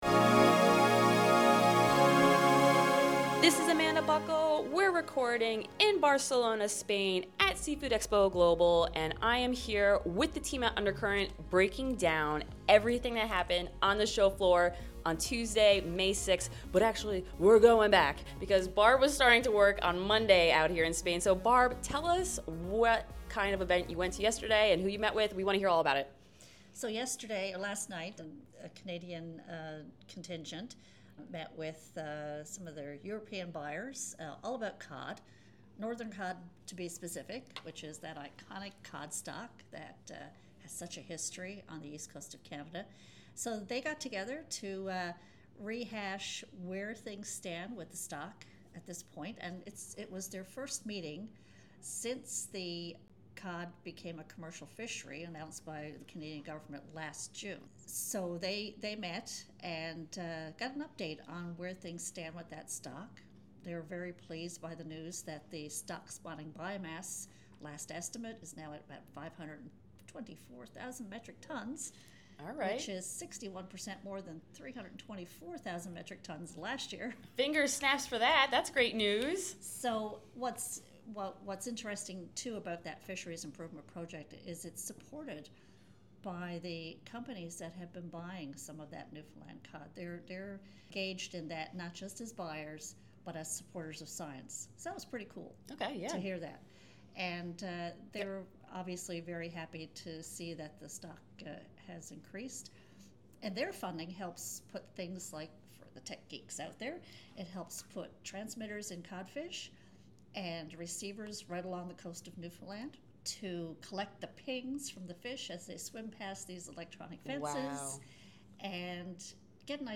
The Undercurrent News team is reporting from Seafood Expo Global in Barcelona, Spain, and Catch the Current is bringing you the inside scoop right from the show floor